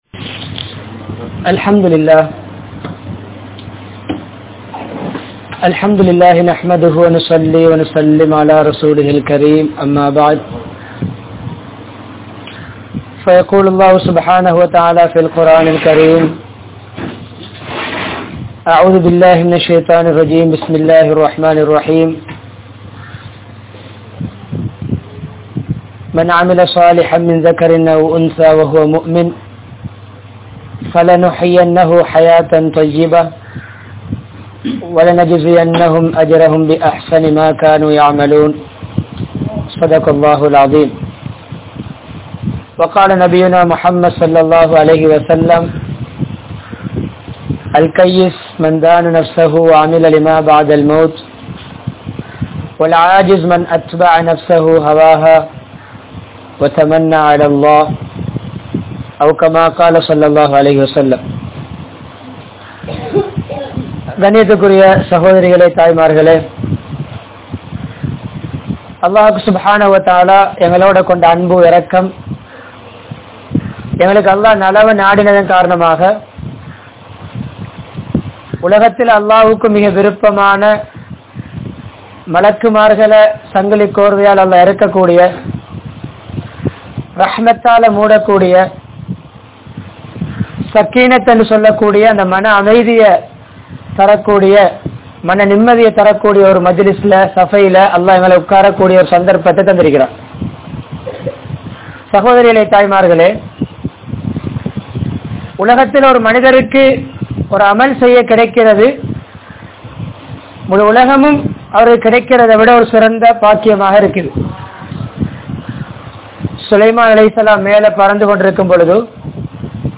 Veettuch Choolalai Seeraakkuvoam (வீட்டுச் சூழலை சீராக்குவோம்) | Audio Bayans | All Ceylon Muslim Youth Community | Addalaichenai
Mannar, Uppukkulam, Al Azhar Jumua Masjidh